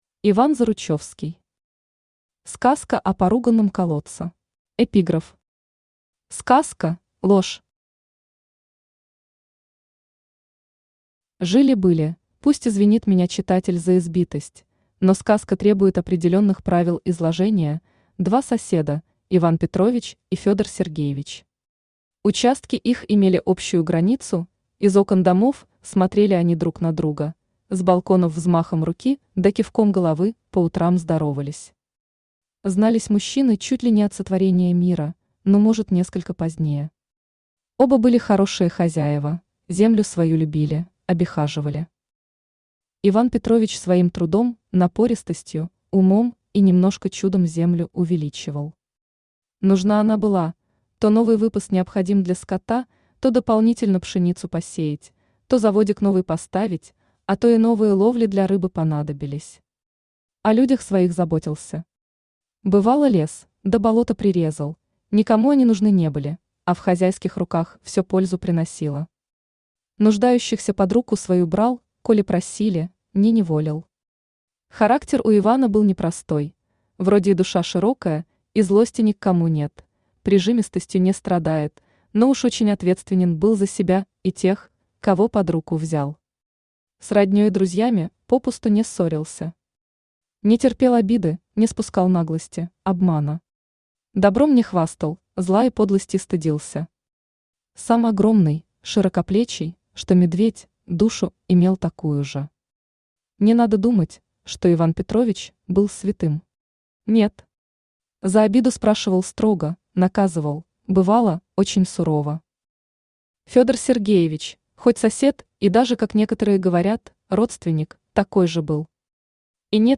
Аудиокнига Сказка о поруганном колодце | Библиотека аудиокниг
Aудиокнига Сказка о поруганном колодце Автор Иван Заручевский Читает аудиокнигу Авточтец ЛитРес.